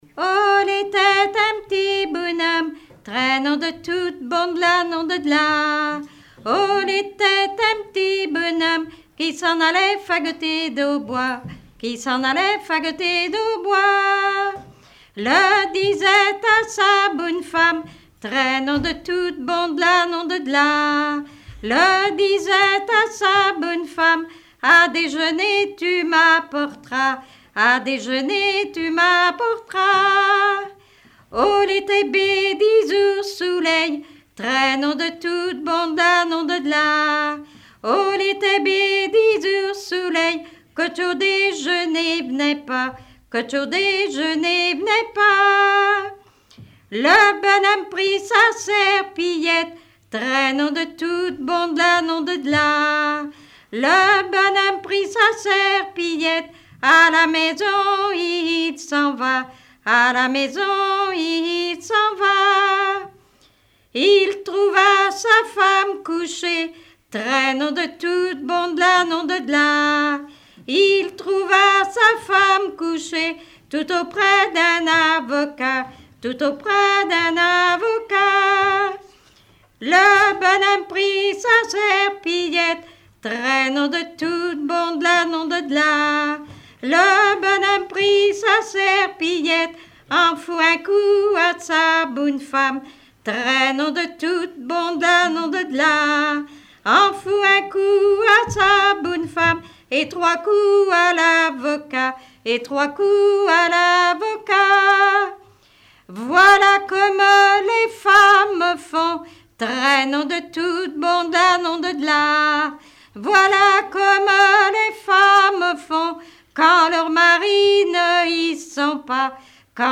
Regroupement de chanteurs du canton
Pièce musicale inédite